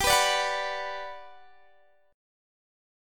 GM7 Chord
Listen to GM7 strummed